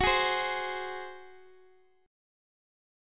F#sus2b5 chord